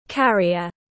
Carrier /ˈkæriər/